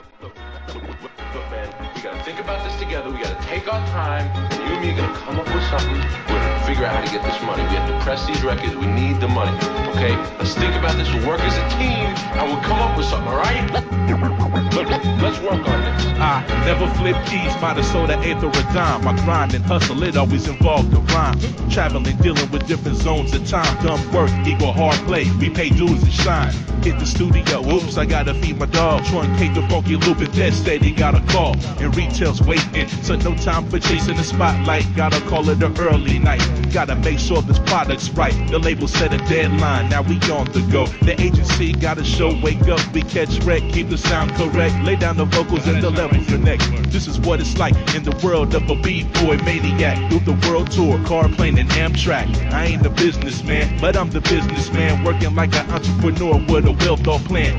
HIP HOP/R&B
心温まるサンプリングの数々